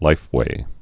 (līfwā)